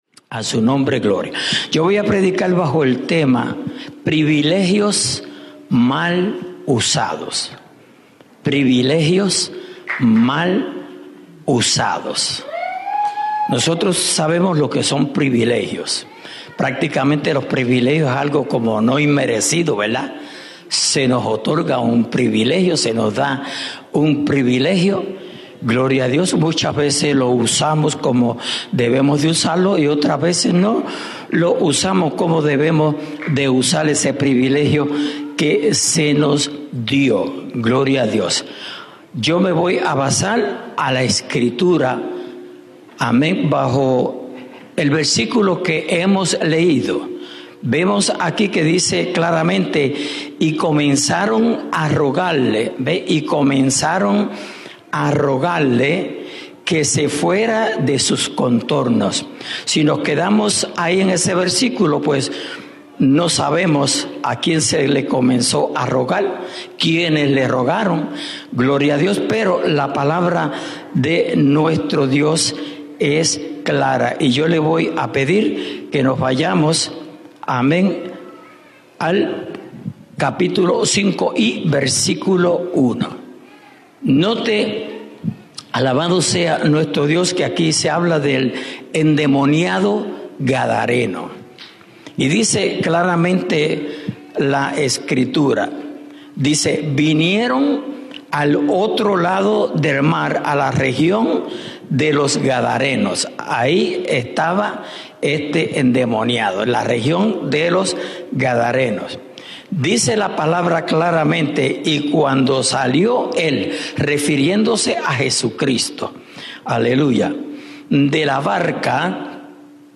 @ Norristown,PA